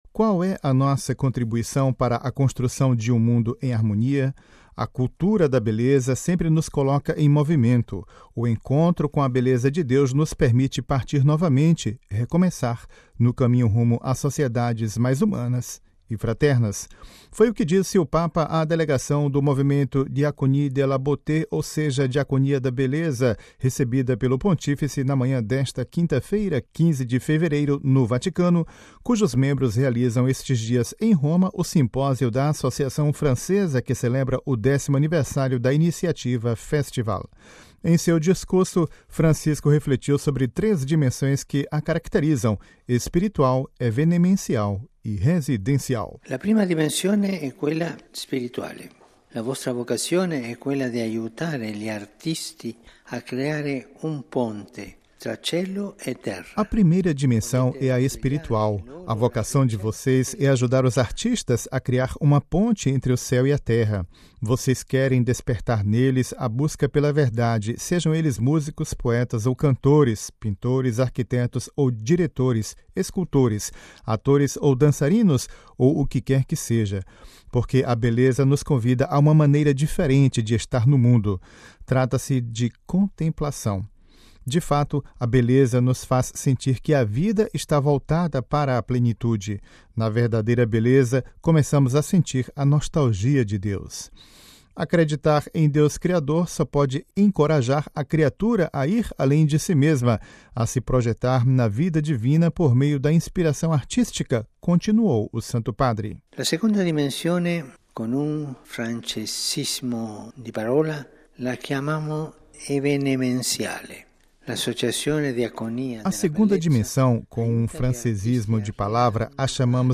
Ouça com a voz do Papa Francisco e compartilhe